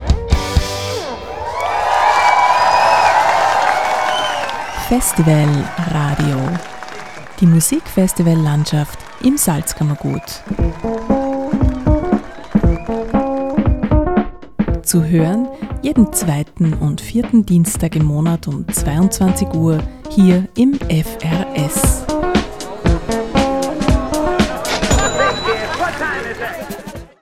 FRS-TRAILER-FESTIVAL-RADIO-JEDEN-2-4-DIENSTAG-22-UHR.mp3